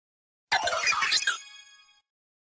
Cri de Terapagos dans Pokémon Écarlate et Violet.